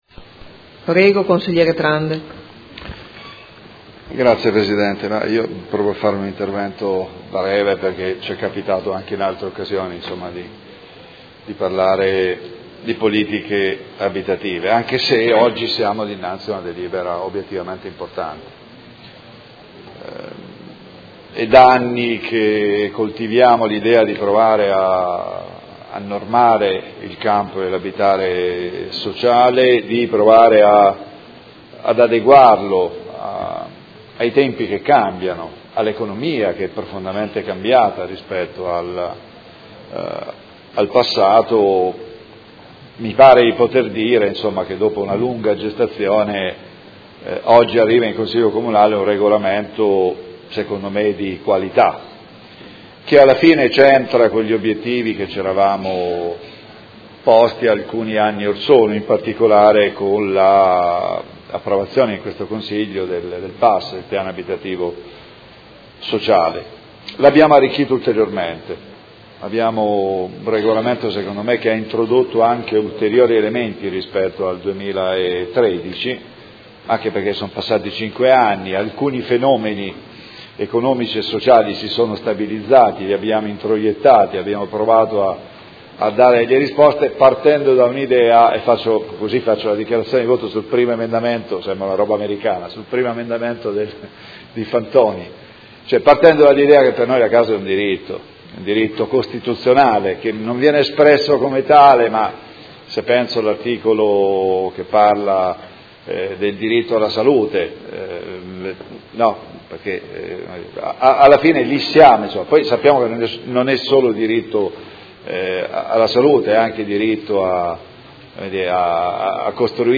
Seduta del 17/05/2018. Dibattito su proposta di deliberazione: Regolamento Edilizia convenzionata e agevolata - Approvazione, ed emendamenti